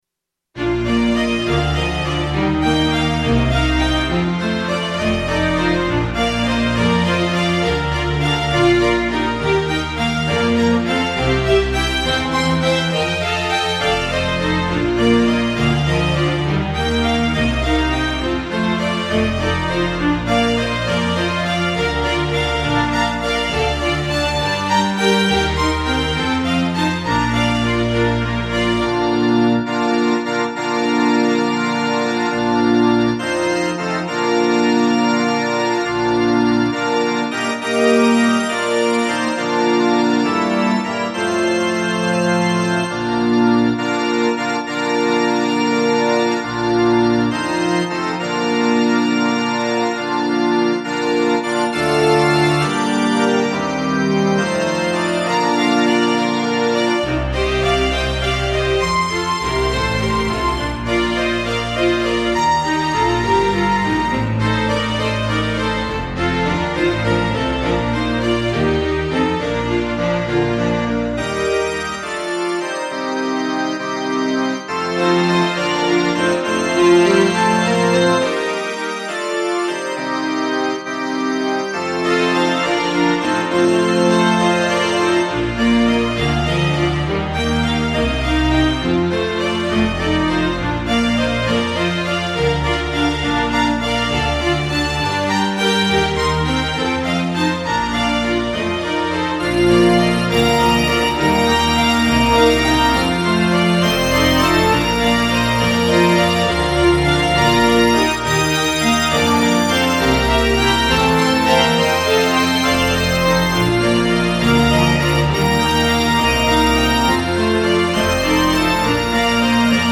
楽器編成は原曲のものですが、ここではシンセサイザーを使っています。